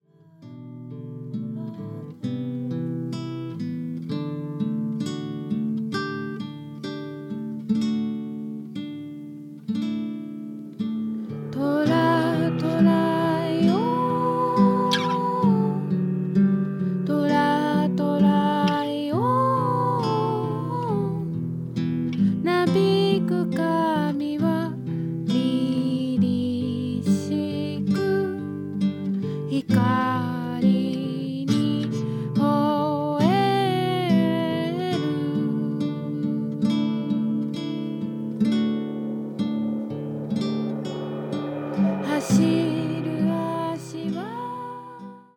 儚くも豊かなメロディーを持つ楽曲
彼らのアングラ的な部分よりも、素朴な中に高い音楽性（と中毒性）が見える、幽玄でメロディアスな好選曲の内容となっています。